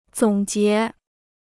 总结 (zǒng jié) Free Chinese Dictionary